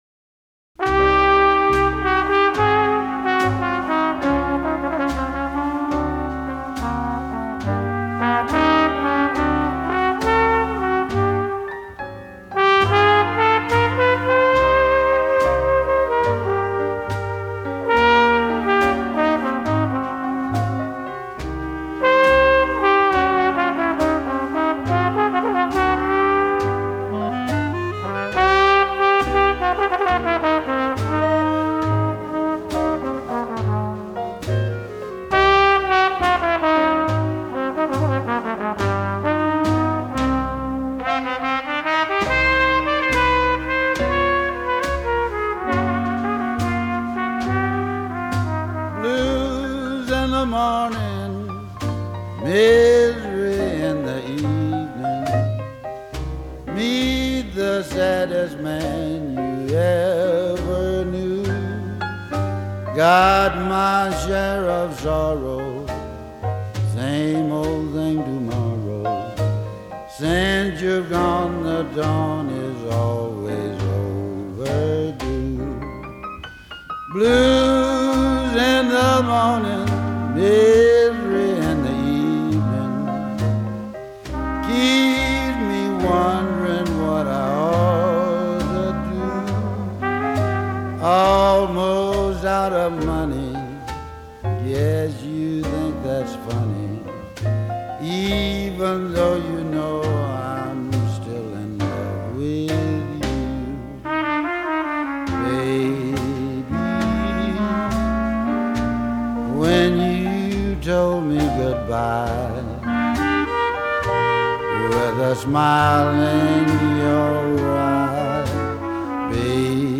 blues/jazz
trumpet